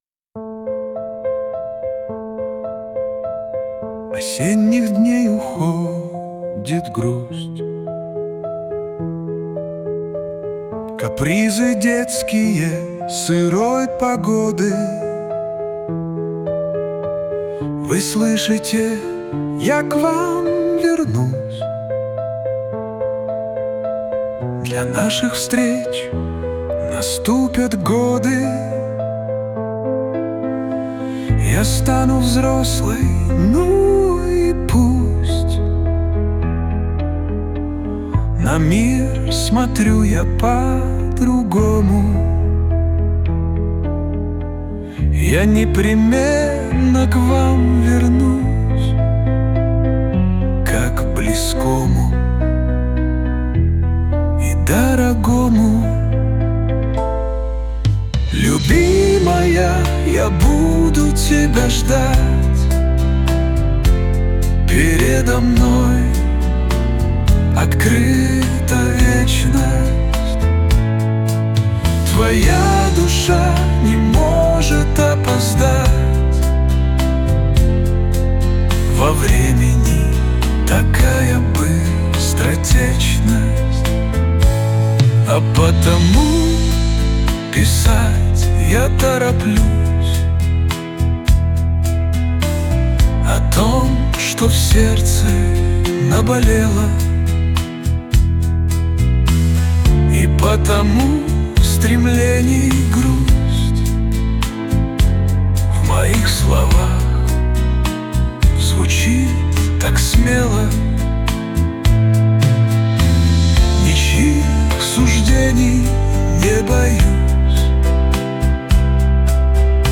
ТИП: Пісня
СТИЛЬОВІ ЖАНРИ: Ліричний
12 12 12 Такий ліричний, мелодійний романс! 12 12